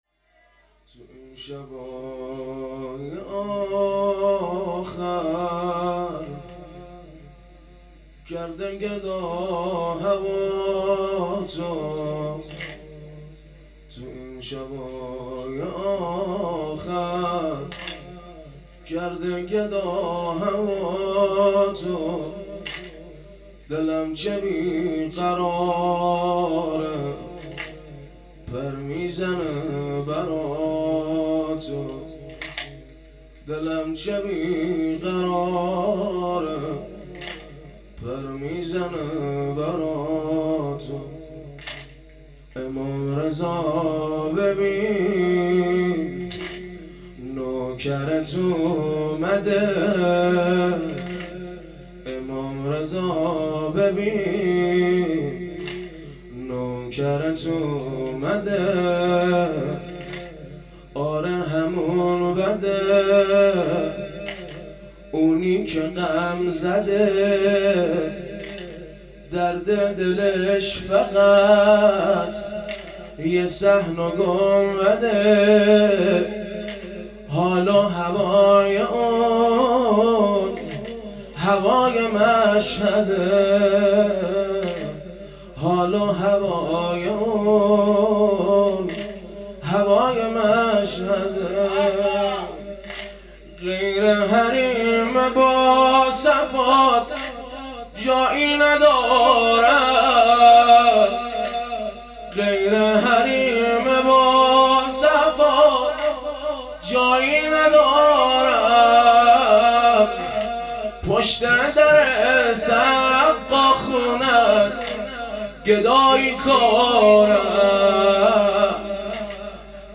شب دوم | مسجد امام موسی بن جعفر علیه السلام | صفر 1440 |هیئت نوجوانان مکتب الرضا علیه السلام | هیئت جوانان لواء الرقیه سلام الله علیها
ویژه برنامه آخر ماه صفر